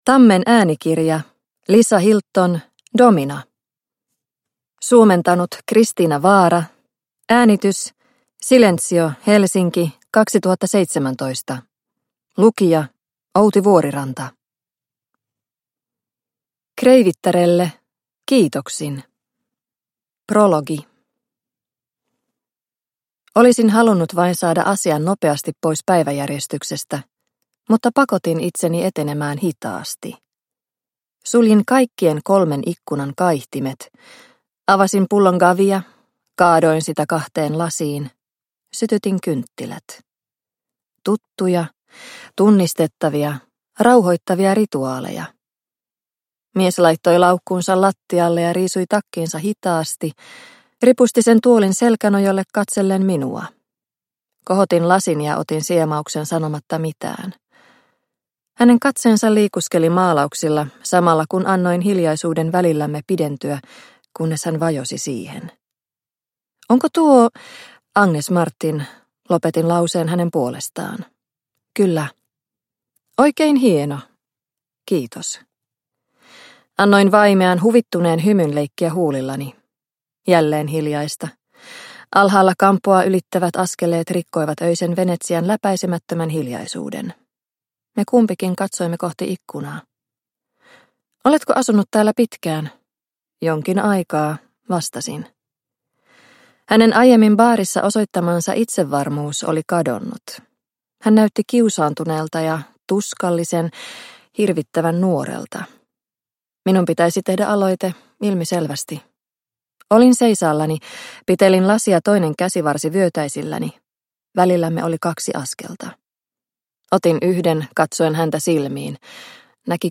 Domina – Ljudbok – Laddas ner